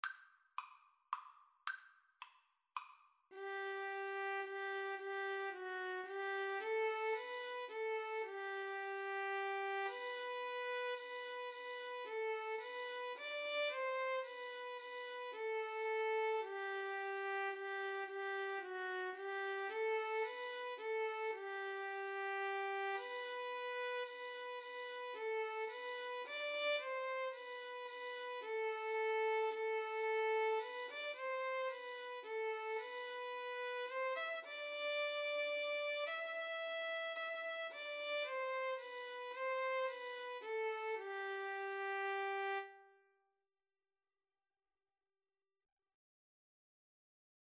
3/4 (View more 3/4 Music)